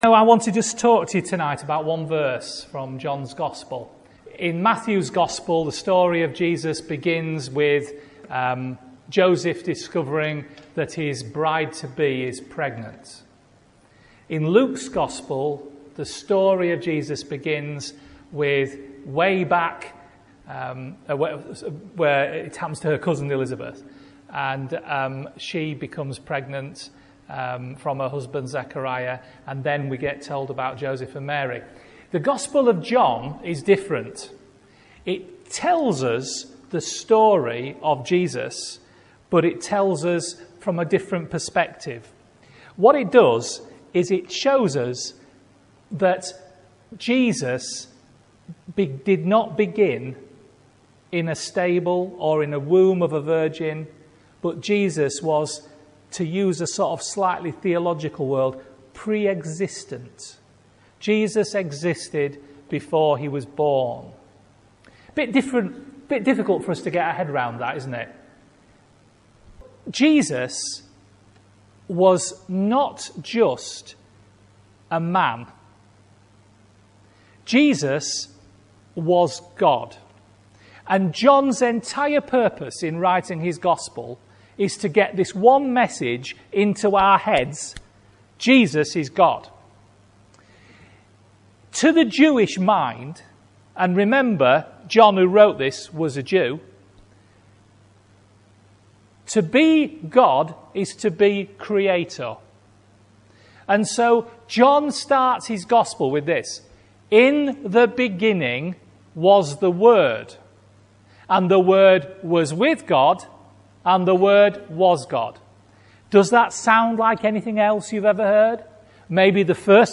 This message is the first from our Sunday night meeting "Celebrate".